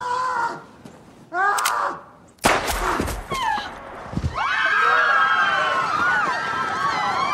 yelling shot echoing woman gasps crowd screaming Sound-Effect].ogg
Original creative-commons licensed sounds for DJ's and music producers, recorded with high quality studio microphones.
[yelling]-[shot-echoing]-[woman-gasps]-[crowd-screaming-sound-effect]_iis.mp3